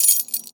R - Foley 194.wav